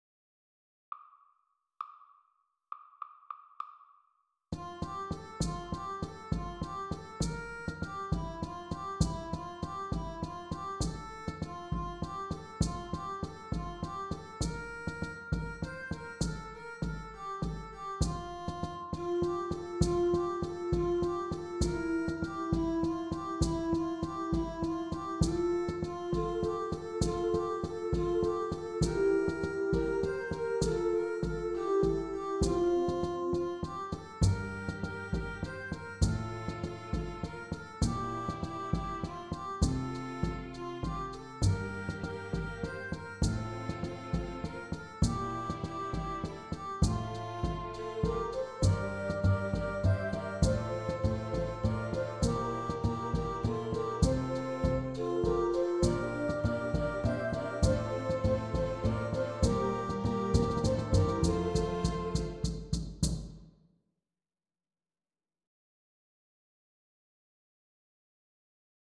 • En b3 se presenta la hemiolia característica del acompañamiento de la muiñeira que aparece en ocasiones en el bombo o en la pandereta dentro del cuarteto tradicional (gaita, tamboril, pandereta, bombo).
arreglo que preparé o hacerte tu propio arreglo con los instrumentos del aula.
video-0501-muinheira-anonima-audio.mp3